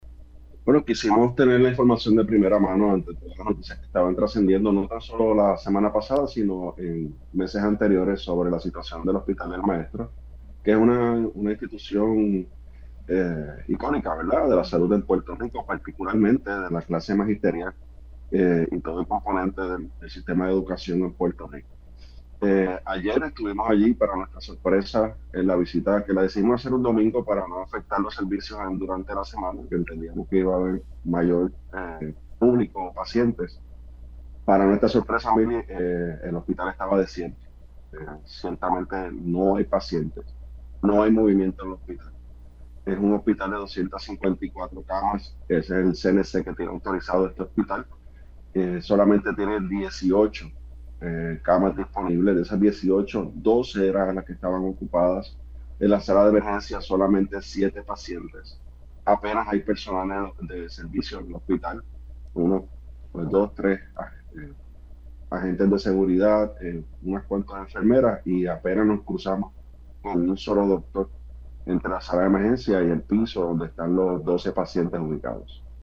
No hay forma de sostenerlo“, detalló el legislador en Pega’os en la Mañana.